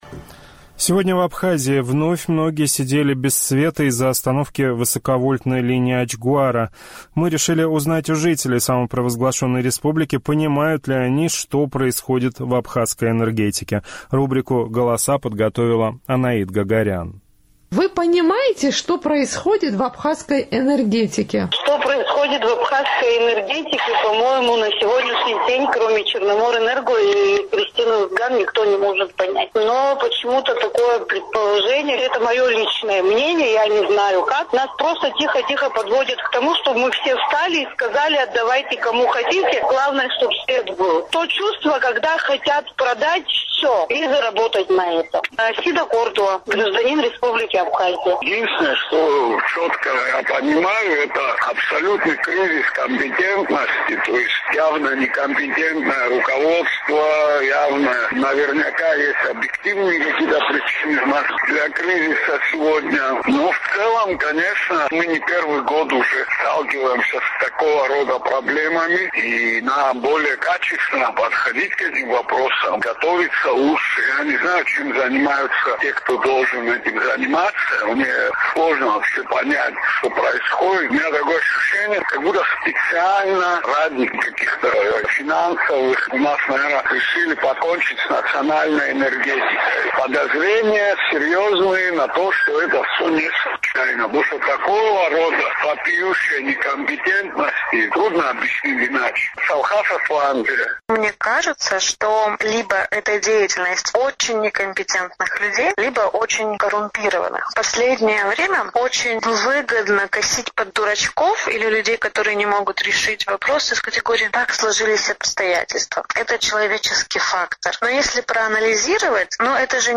Сухумский опрос – о том, что происходит в абхазской энергетике
В Абхазии сегодня вновь отключили высоковольтную линию «Ачгуара», люди часами сидят без света. «Эхо Кавказа» поинтересовалось у местных жителей, каковы, на их взгляд, причины энергетического кризиса.